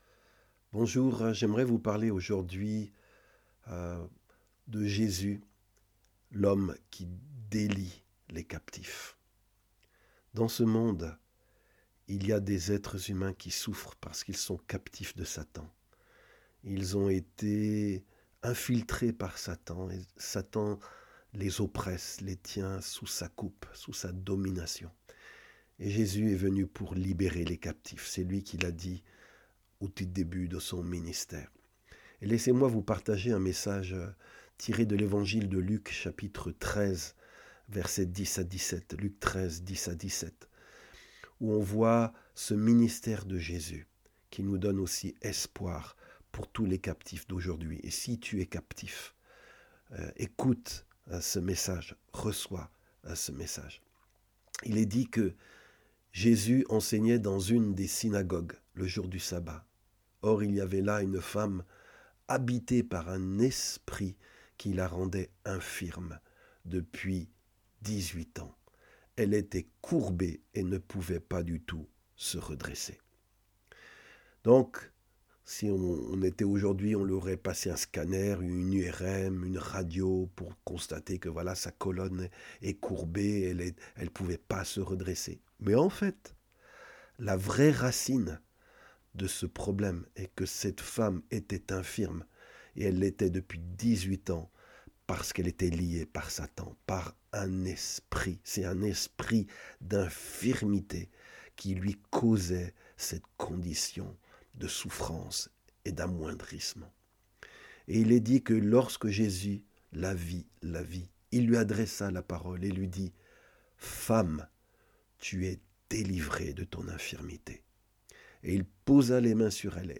Des messages audio chrétiens